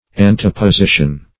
Search Result for " anteposition" : The Collaborative International Dictionary of English v.0.48: Anteposition \An`te*po*si"tion\, n. [Cf. LL. antepositio.